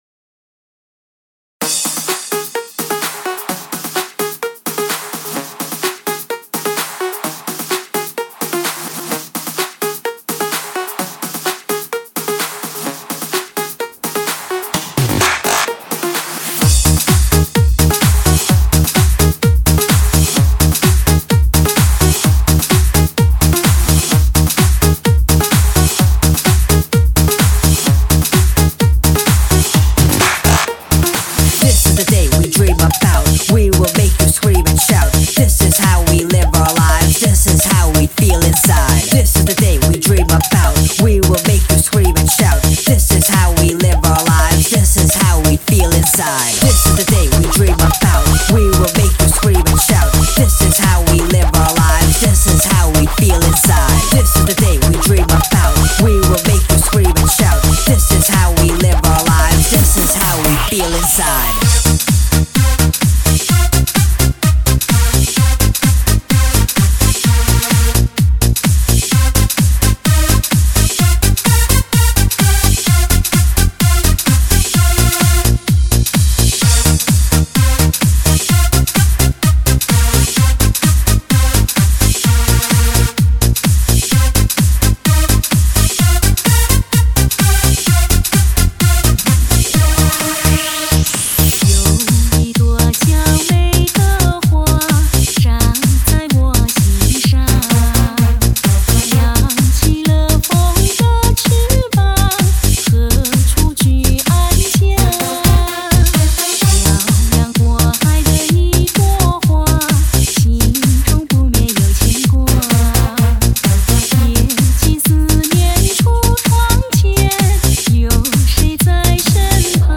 年度最嗨天曲 舞动你火热的心.
最佳夜场的首选男嗨女爽舞曲.
高能量放电最激情舞曲来袭全体舞动起来!